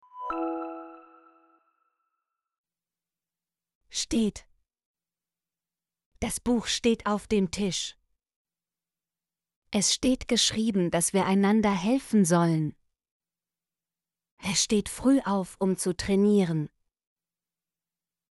steht - Example Sentences & Pronunciation, German Frequency List